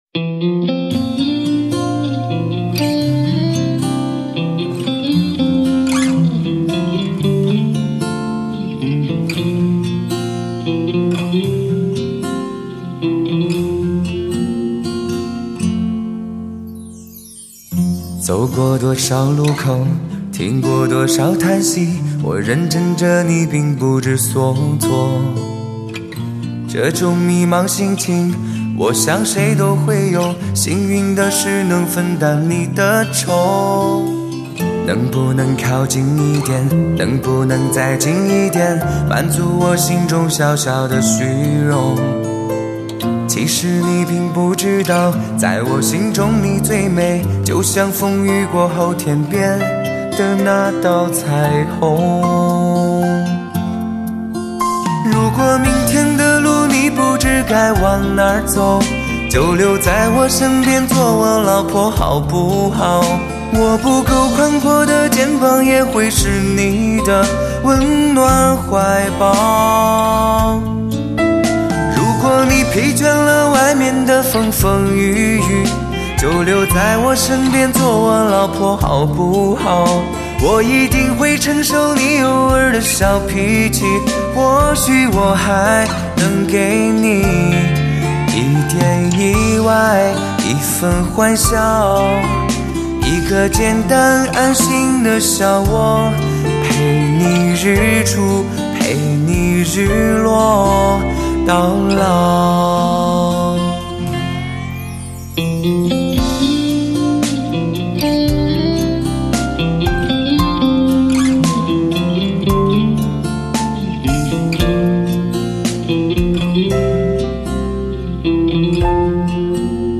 这是一张纯粹心与心真诚对谈的专辑，透过人声连结心与心最真诚的距离，为人们呈现最真实的原音，表现最诚挚的感情。